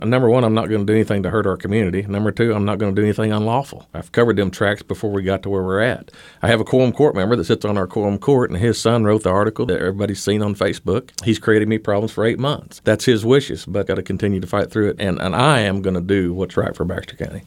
Judge Litty shared his opinion with KTLO, Classic Hits and the Boot News on the source of the allegations.